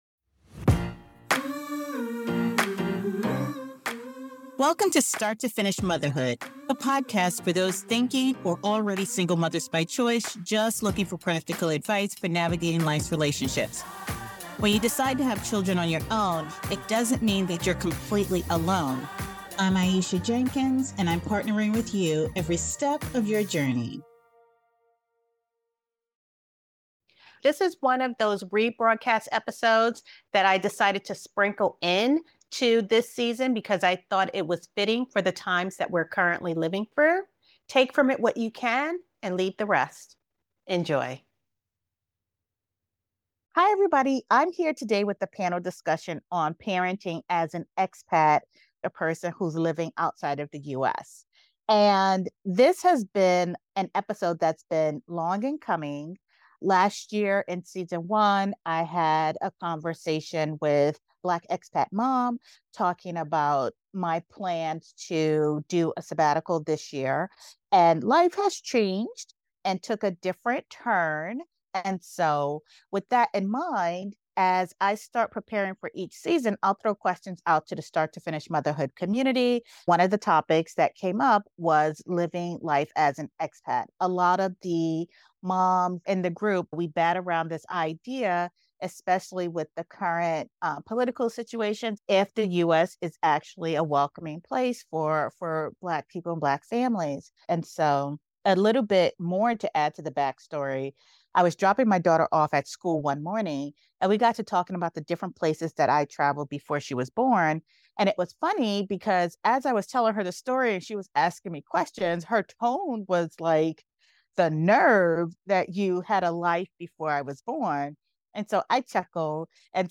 Through an engaging panel discussion, they explore the unique challenges and joys of parenting abroad, from the decision to move for a fresh start to the day-to-day experiences that shape their new lives in Mexico.